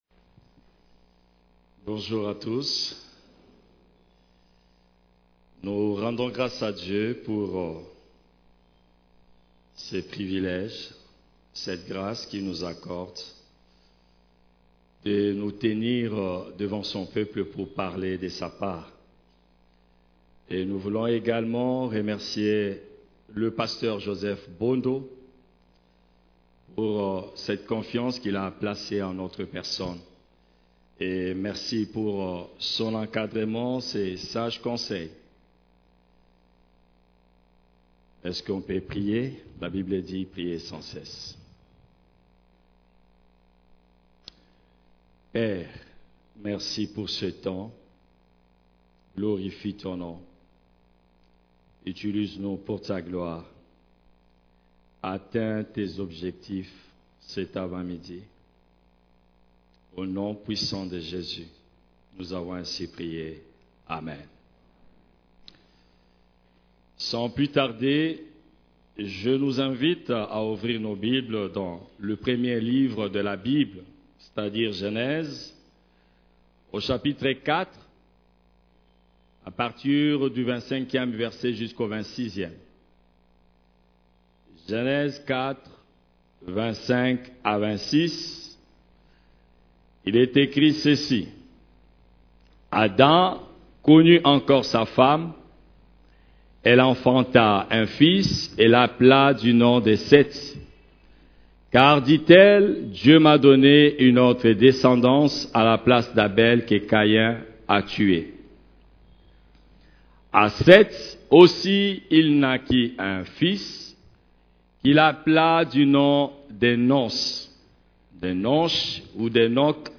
CEF la Borne, Culte du Dimanche, Serviteur, seul poste vacant dans l'Eglise